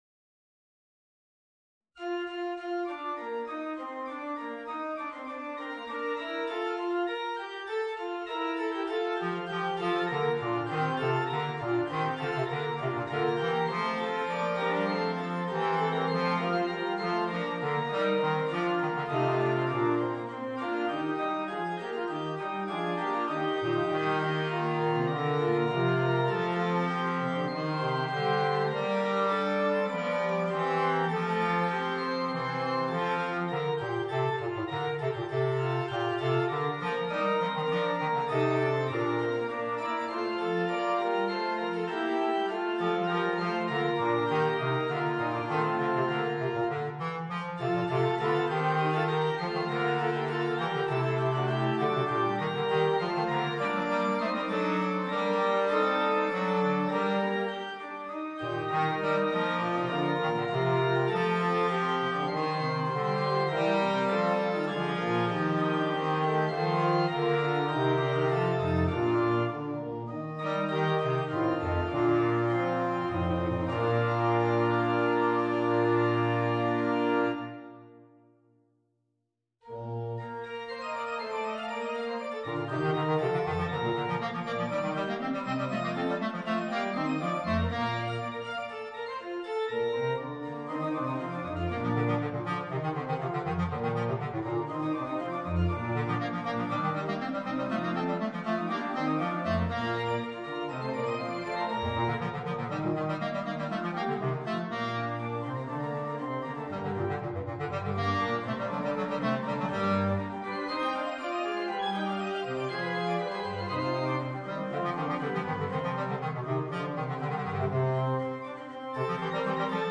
Voicing: Bass Clarinet and Organ